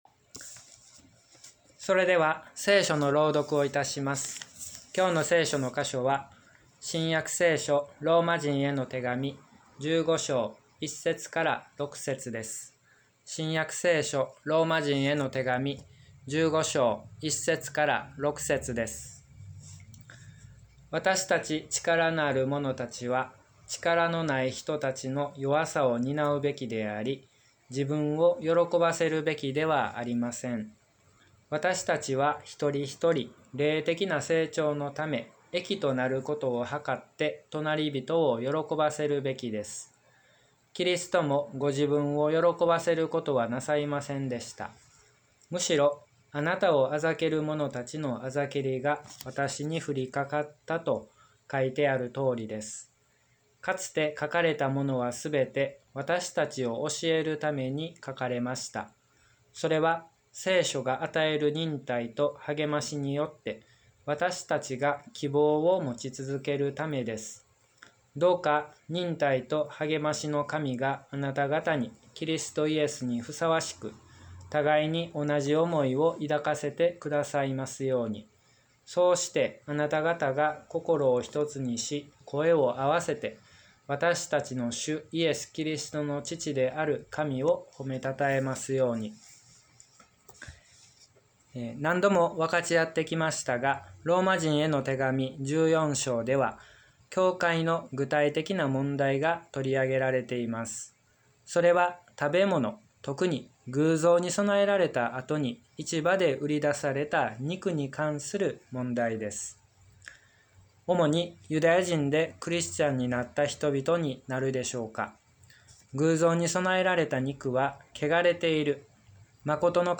礼拝説教から ２０２１年８月１５日